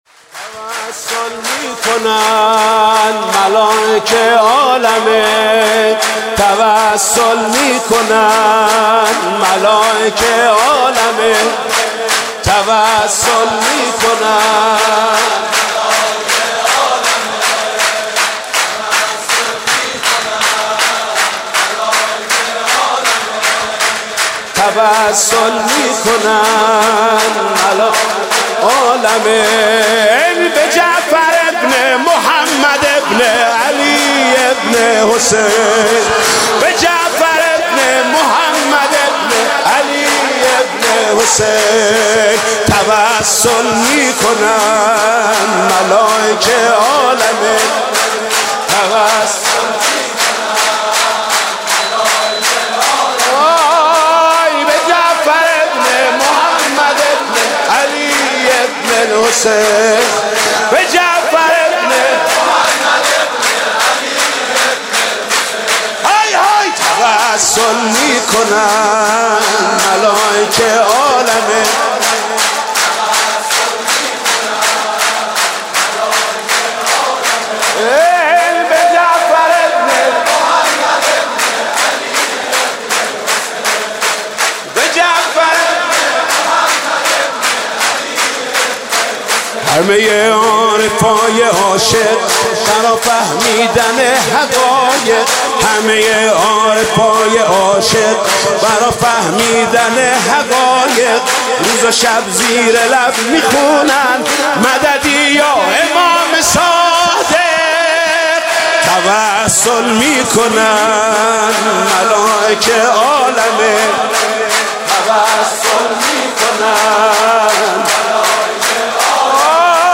مداحی حاج محمود کریمی ویژه ولادت امام صادق (ع)+صوتی
تهران_الکوثر: مداحی بسیارزیبا با نوای حاج محمود کریمی به نام "شیعه ی جعفری ام" ویژه سالروز ولادت امام صادق علیه السلام و پیامبر اکرم صلی الله علیه و آله و سلم را بشنوید.
مولودی خوانی